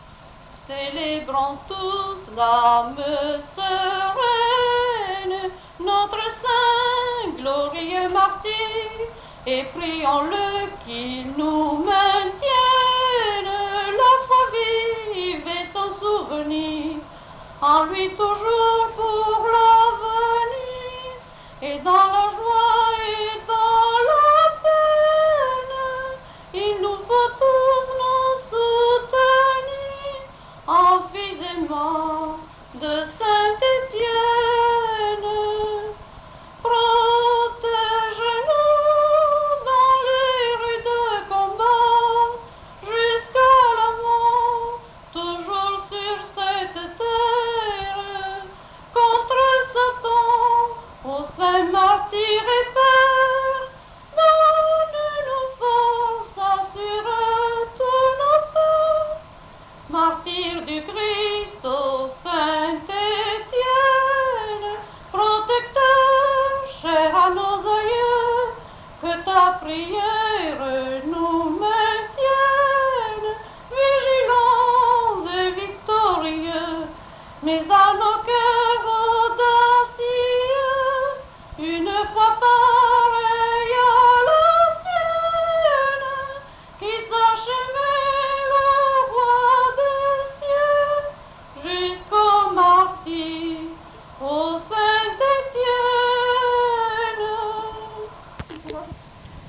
Lieu : Mas-Cabardès
Genre : chant
Effectif : 1
Type de voix : voix de femme
Production du son : chanté
Contextualisation de l'item : cantique.